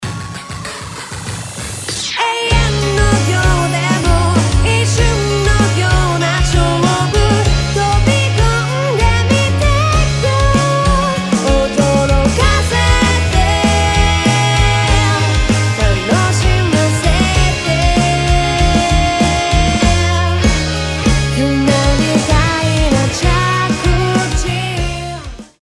「リアンプ対象楽曲」
この楽曲のボーカル・ベース・ドラムトラックに対してリアンプを行っていきます。